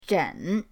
zhen3.mp3